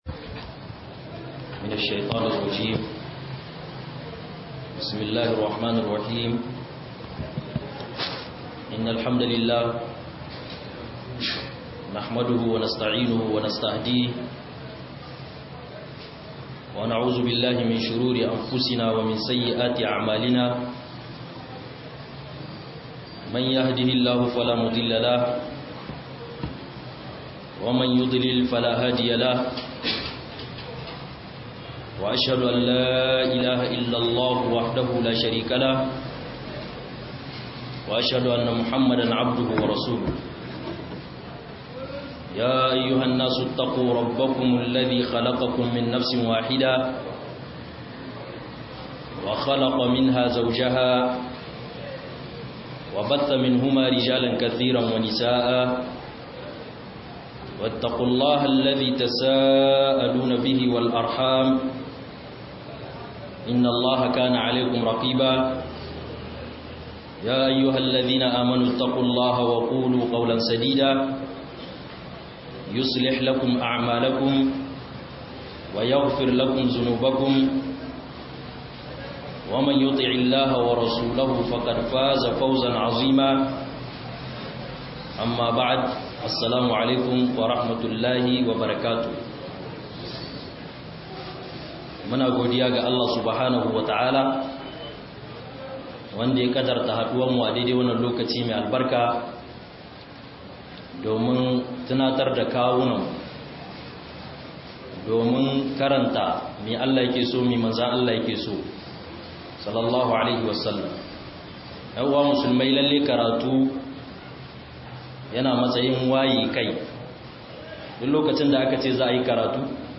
HADARIN-ZAMA-DA-ABOKIN-BANZA - MUHADARA